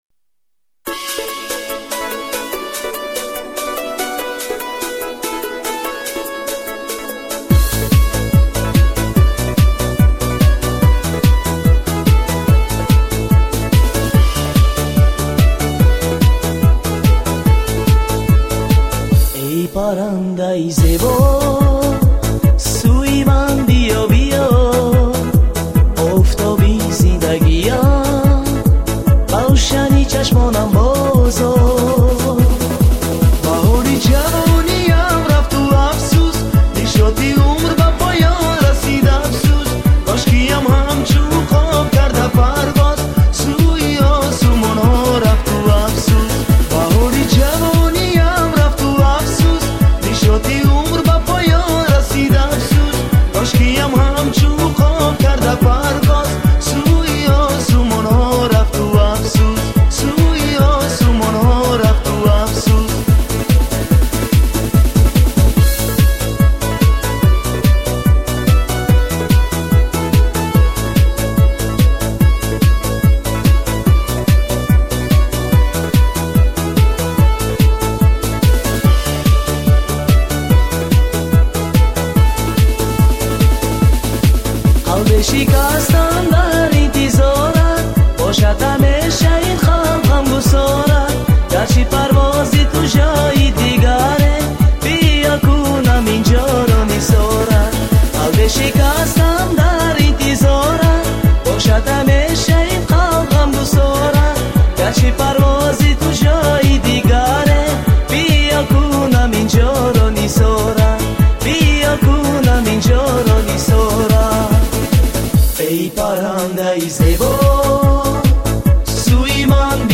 را با صدای خوانده تاجیک بشنوید: